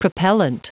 Prononciation du mot propellant en anglais (fichier audio)
Prononciation du mot : propellant